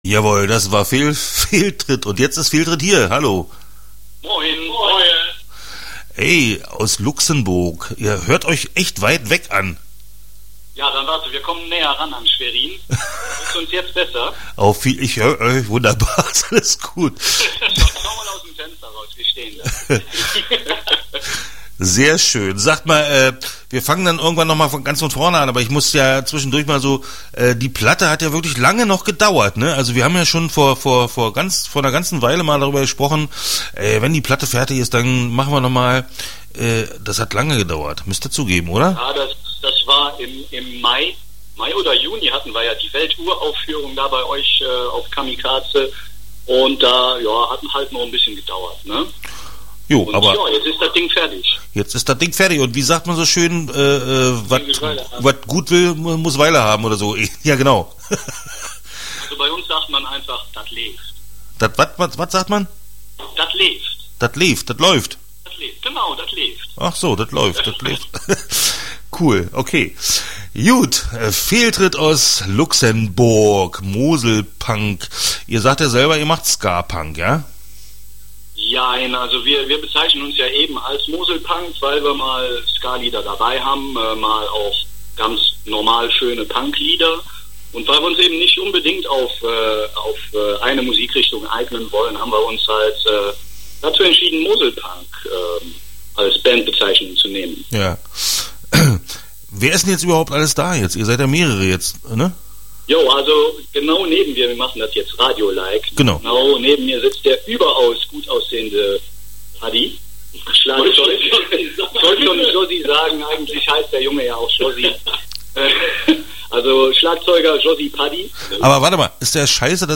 Interview Fehl-Tritt Teil 1 (15:01)